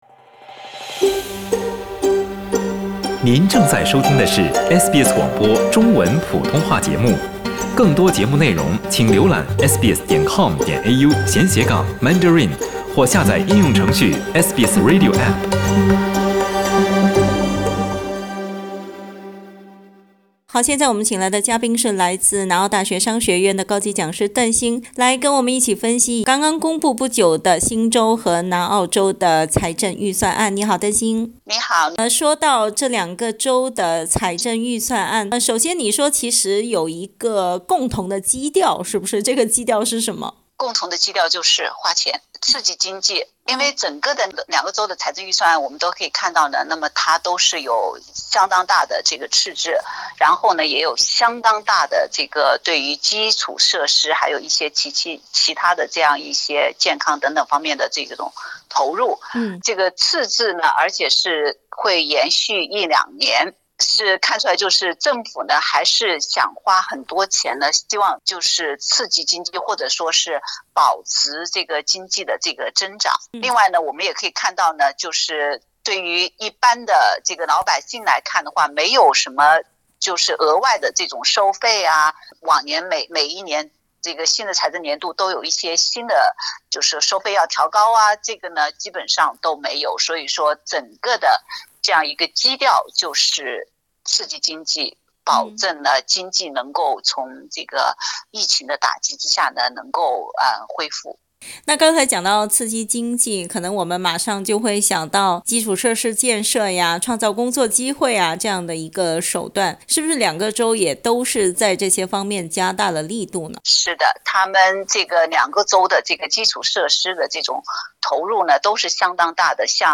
（请听采访，本节目为嘉宾观点，不代表本台立场） 澳大利亚人必须与他人保持至少1.5米的社交距离，请查看您所在州或领地的最新社交限制措施。